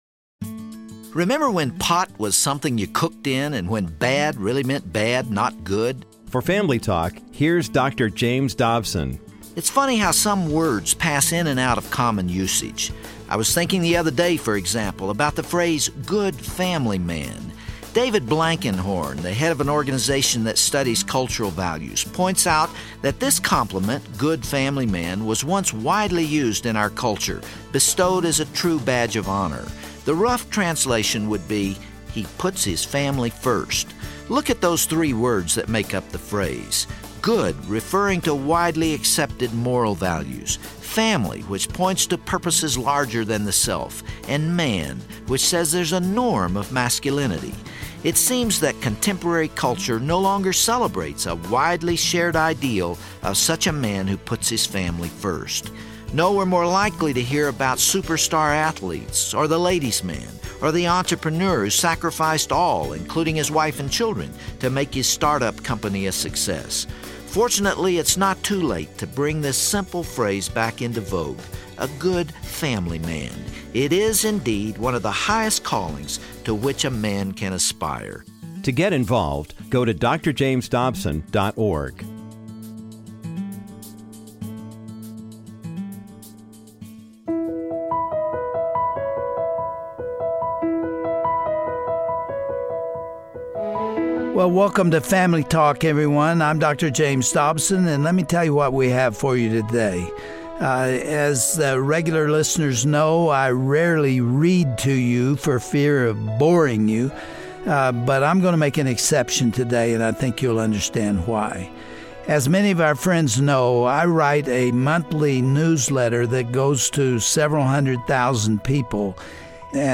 Dr. Dobson reads his June newsletter, which focuses on this assault against biblical manhood. He reviews Americas moral slide away from godliness, and identifies the various ways men are undermined and ruthlessly criticized in this society.